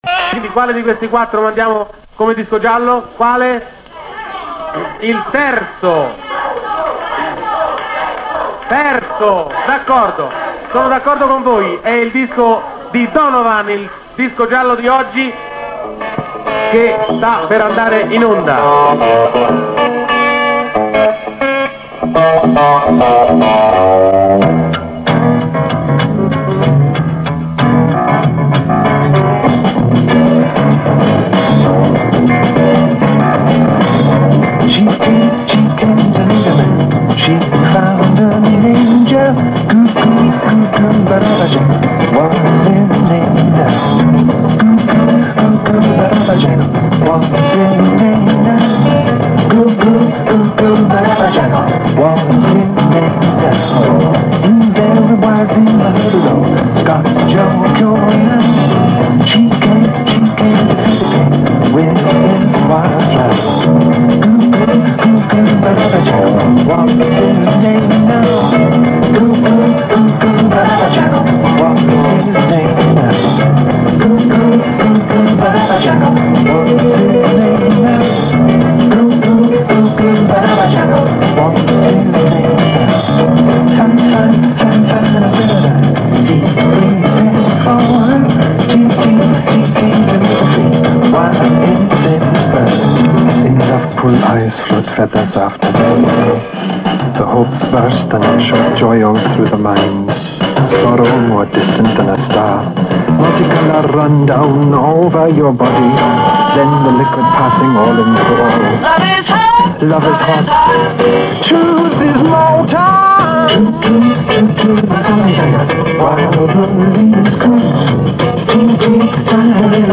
I brani musicali sono sfumati per le solite esigenze di tutela del copyright.
Da Bandiera gialla. Gianni Boncompagni in finale della popolare trasmissione, la prima della RAI esplicitamente pensata per il nuovo mondo giovanile, chiede ai ragazzi presenti di scegliere il disco giallo.